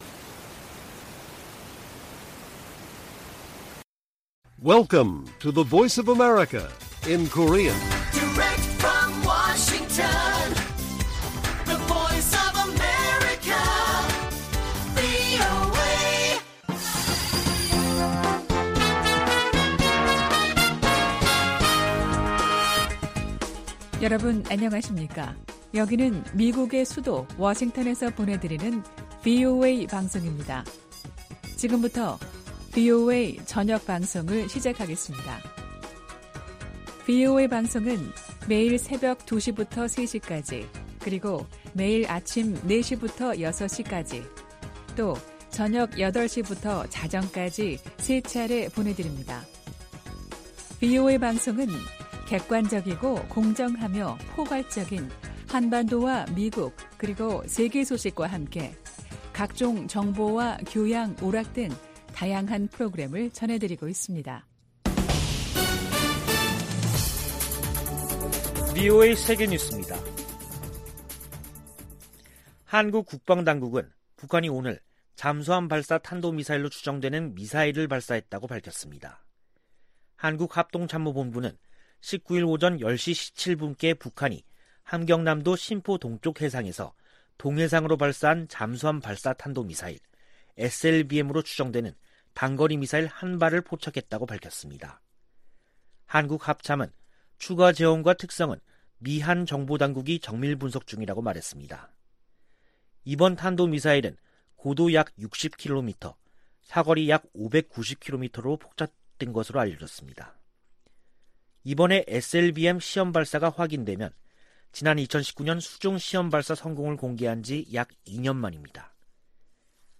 VOA 한국어 간판 뉴스 프로그램 '뉴스 투데이', 2021년 10월 19일 1부 방송입니다. 한국 합동참모본부는 북한이 동해상으로 잠수함발사 탄도미사일(SLBM)로 추정되는 단거리 미사일 1발을 발사했음을 포착했다고 밝혔습니다. 미국과 한국, 일본 정보수장이 서울에서 만나 단거리 미사일 발사 등 북한 문제를 협의했습니다. 성 김 미 대북특별대표는 한국 정부가 제안한 종전선언에 관해 계속 논의할 것이라고 밝혔습니다.